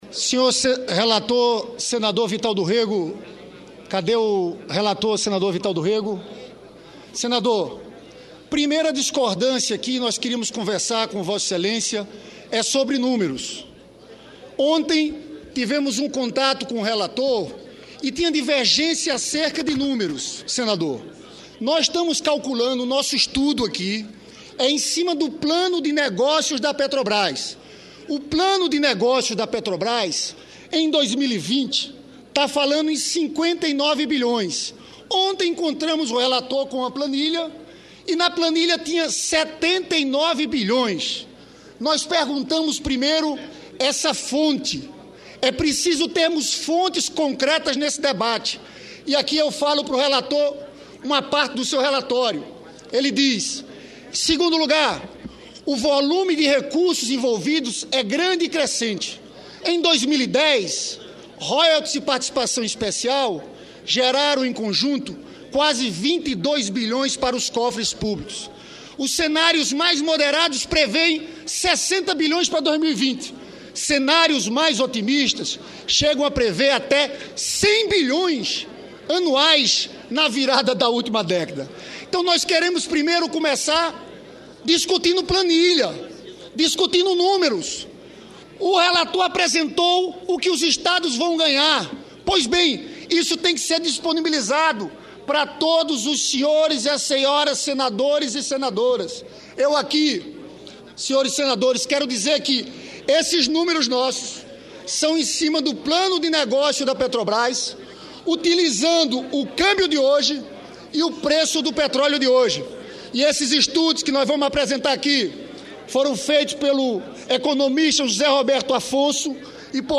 Pronunciamento do senador Lindbergh Farias